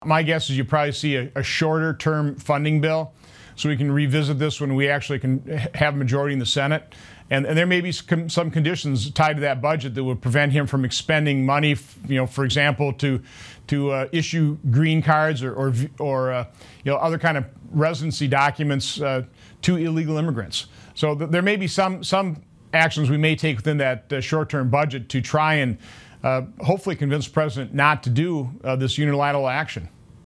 Senator Johnson gave these answers during an interview on Wednesday, Nov. 12, with WFRV-TV